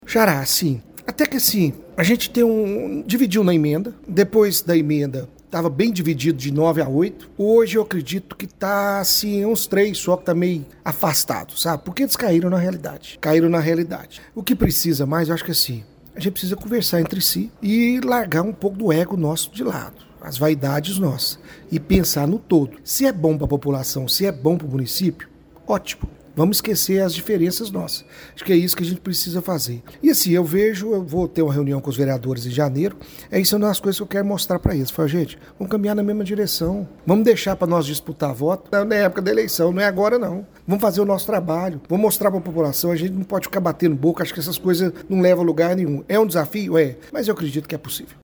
Questionado pela reportagem do Portal GRNEWS sobre a necessidade de ajustes internos entre os vereadores, ele respondeu que deve se reunir com todos em janeiro, mas acredita que maioria dos parlamentares já entendeu a necessidade de dialogar mais e trabalhar pelo bem de Pará de Minas: